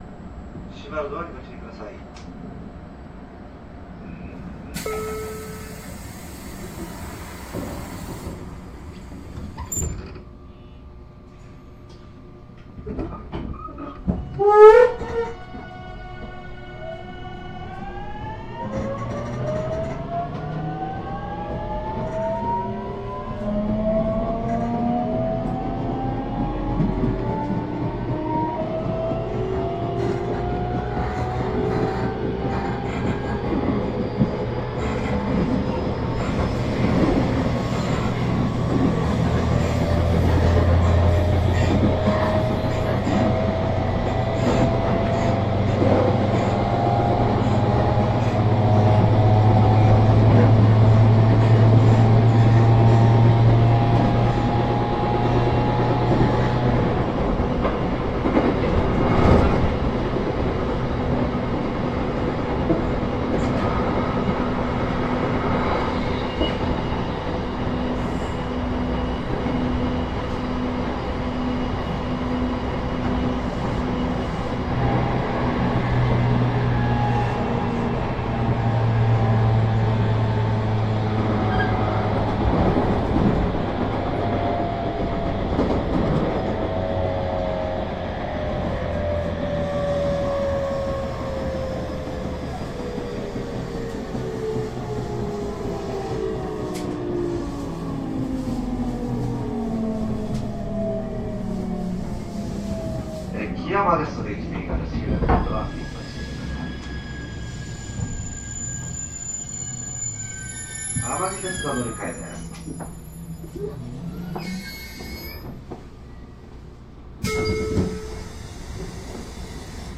変調音は東芝GTOそのものですが、1段階目の変調音が短めです。制動時は発電ブレーキが使用されるので、減速時には変調音が掻き消されたようになります。
走行音（クモハ813-111）
収録区間：鹿児島本線 けやき台→基山